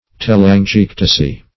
Telangiectasy \Tel*an`gi*ec"ta*sy\, n.
telangiectasy.mp3